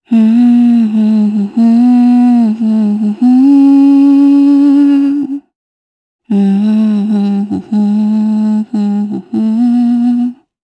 Valance-Vox_Hum_jp.wav